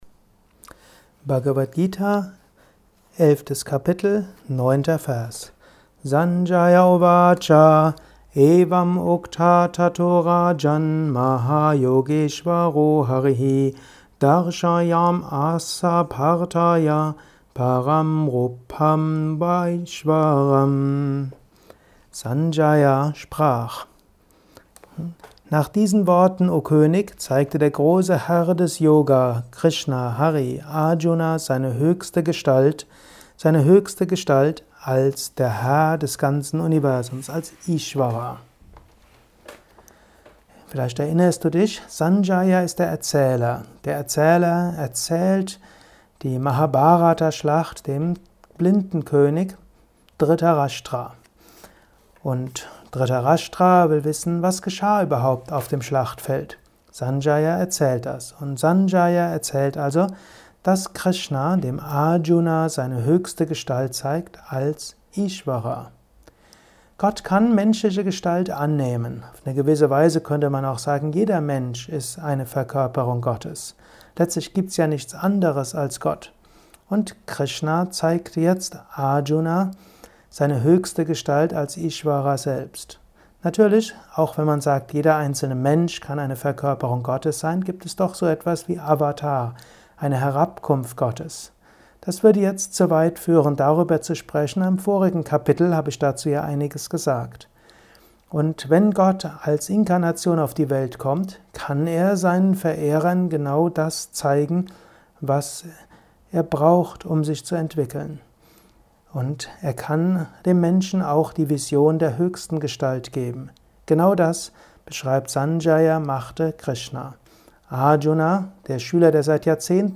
Dies ist ein kurzer Kommentar als Inspiration für den heutigen
Aufnahme speziell für diesen Podcast.